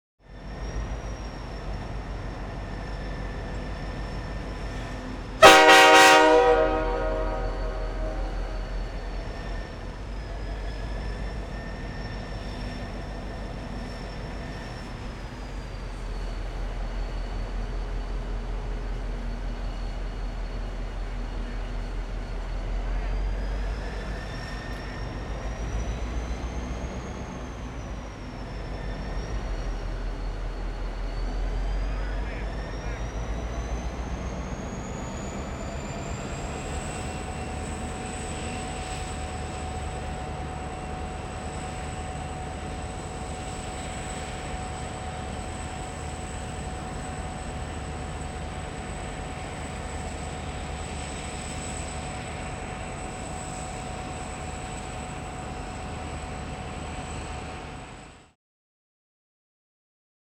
transport
Train Idle Ext Med Close Horn